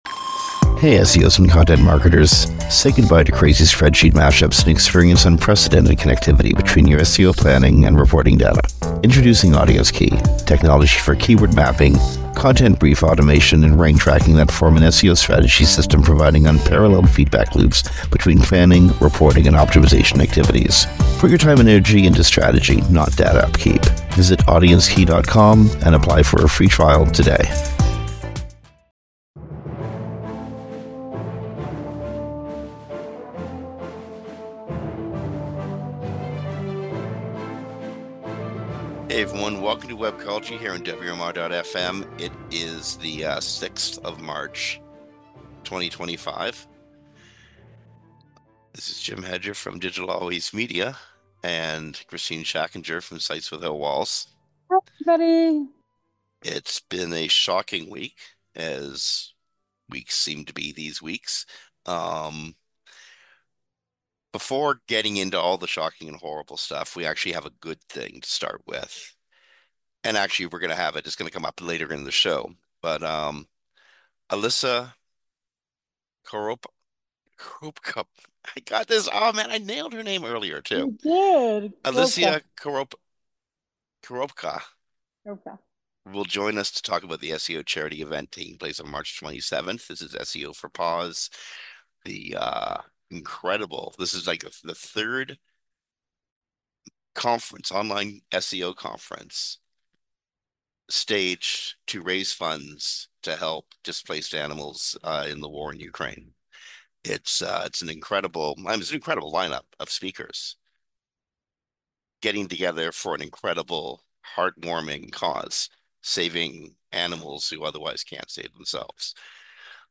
Based on interviews with special high-profile guests or panels, Webcology introduces, explores and explains how the various segments of the web marketing world work.